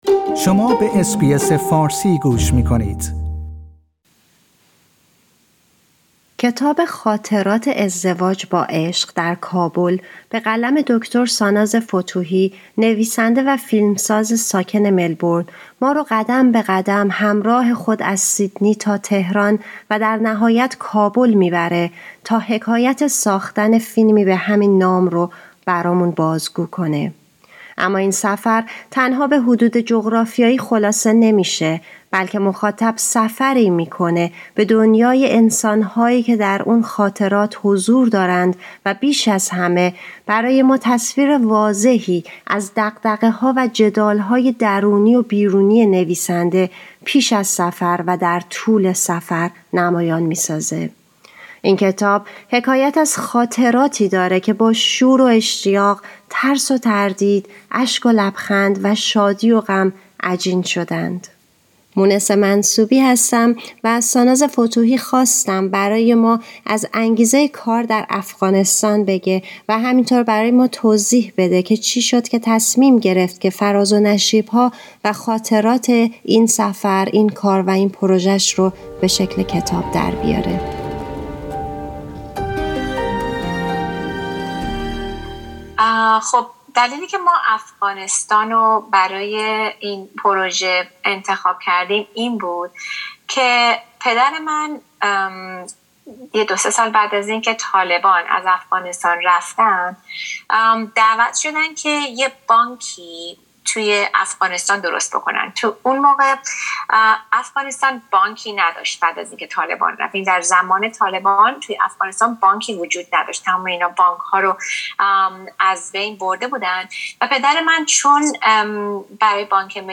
در این گفتگو